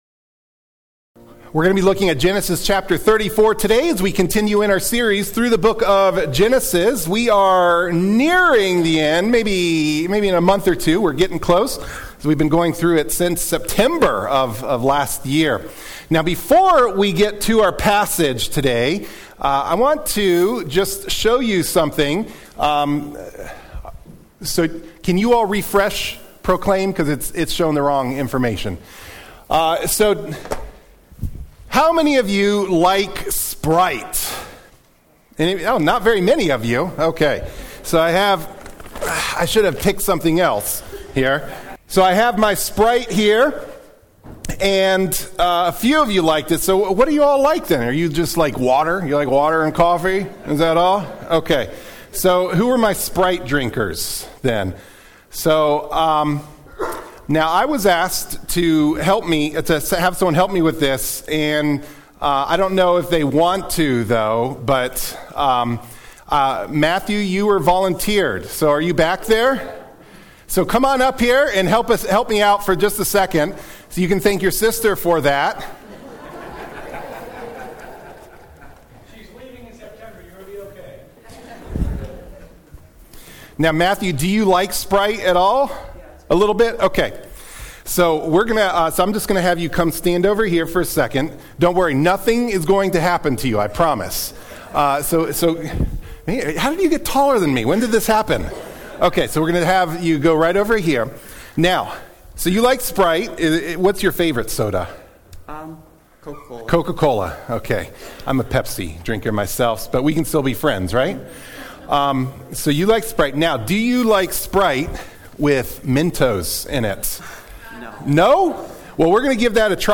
Sermon-4-6-25-MP3-for-Audio-Podcasting.mp3